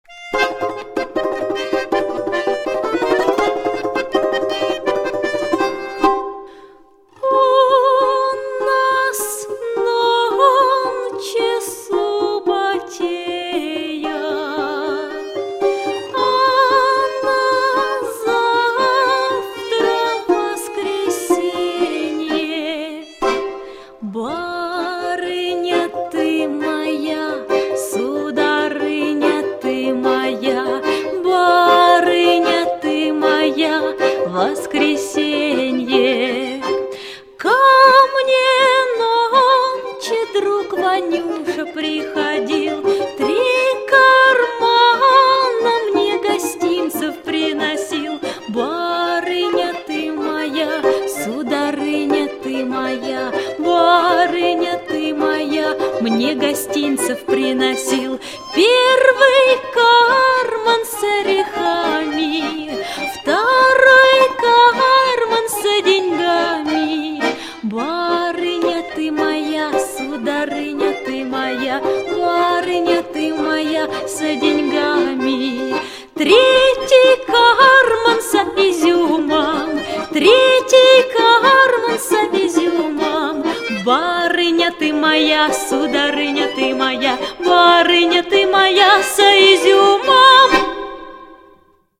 Музыкальное сопровождение: балалайка и гармонь